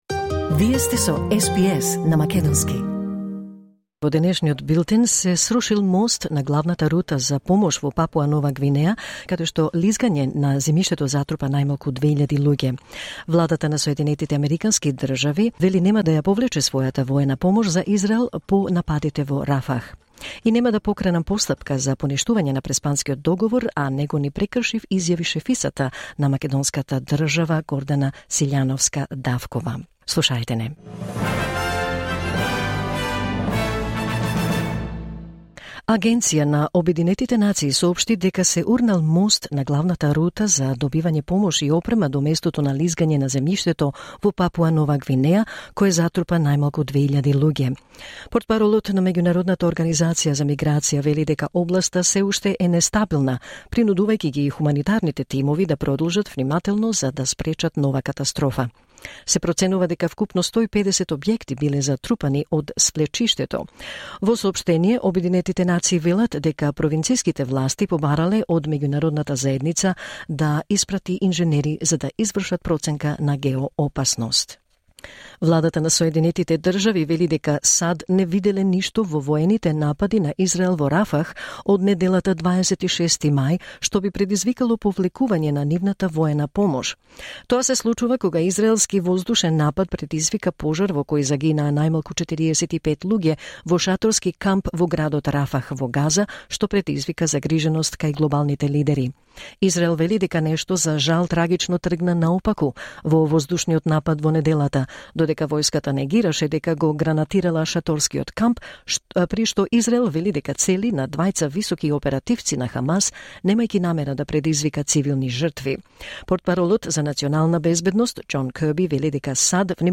Вести на СБС на македонски 29 мај 2024